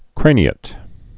(krānē-ĭt, -āt)